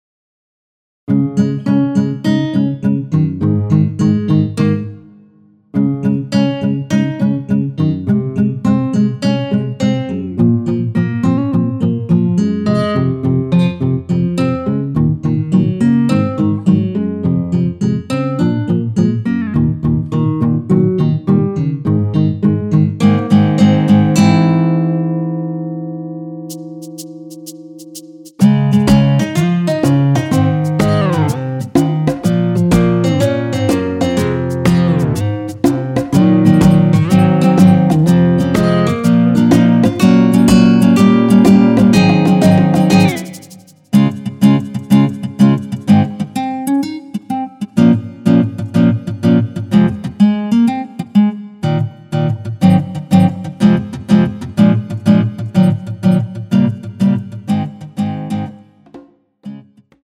전주 없이 시작 하는곡이라 노래 하시기 편하게 전주 2마디 많들어 놓았습니다.(미리듣기 확인)
원키에서(-3)내린 MR입니다.
Db
앞부분30초, 뒷부분30초씩 편집해서 올려 드리고 있습니다.